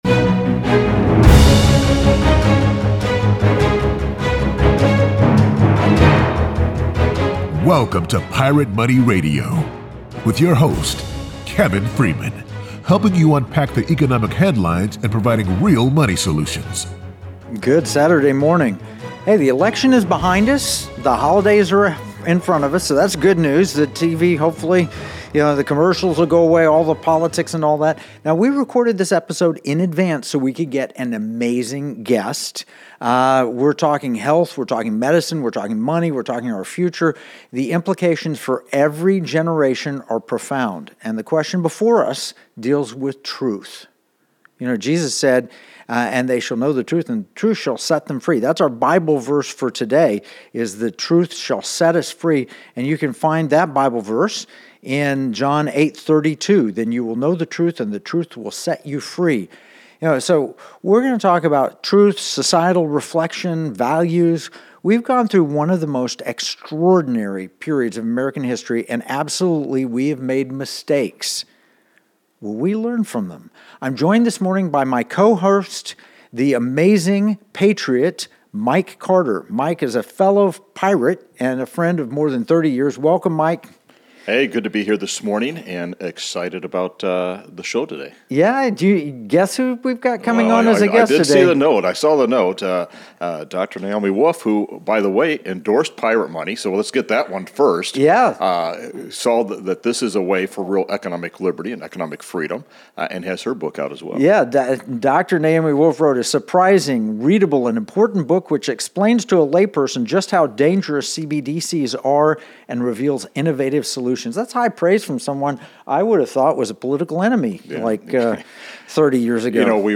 Unpacking the Pfizer Papers: A Journey to Truth and Liberty | Guest: Dr. Naomi Wolf | Ep 033 | Pirate Money Radio